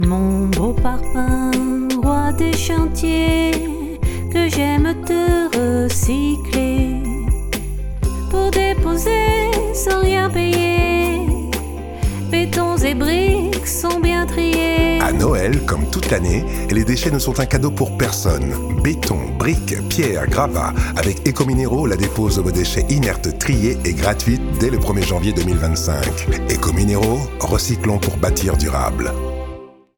spot radio original